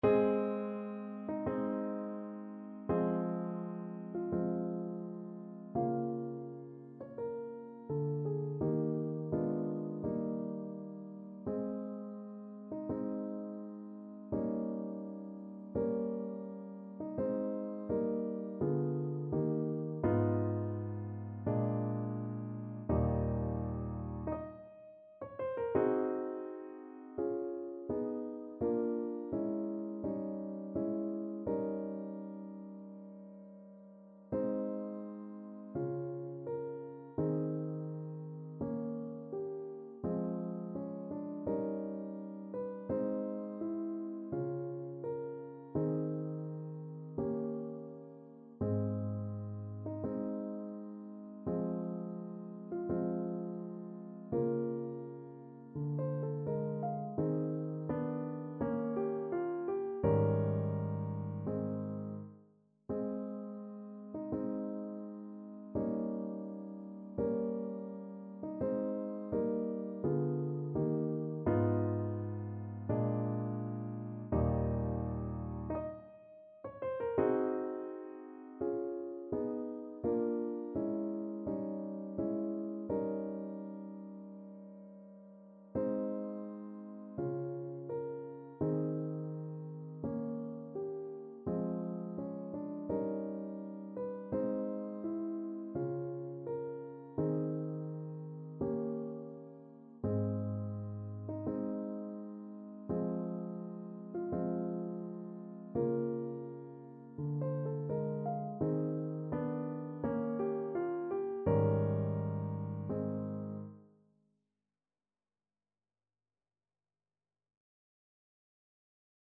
Play (or use space bar on your keyboard) Pause Music Playalong - Piano Accompaniment Playalong Band Accompaniment not yet available transpose reset tempo print settings full screen
Ab major (Sounding Pitch) F major (Alto Saxophone in Eb) (View more Ab major Music for Saxophone )
~ = 42 Sehr langsam
Classical (View more Classical Saxophone Music)